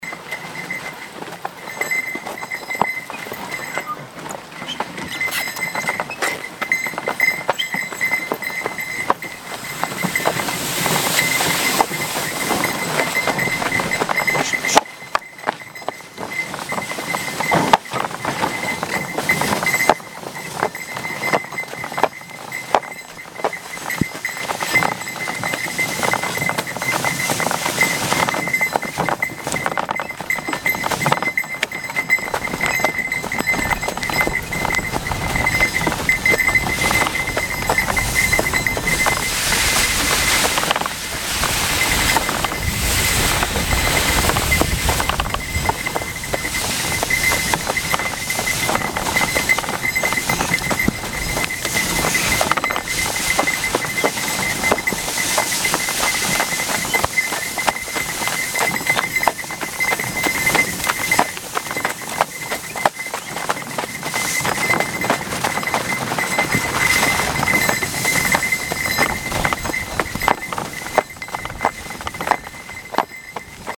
Езда на санях с лошадью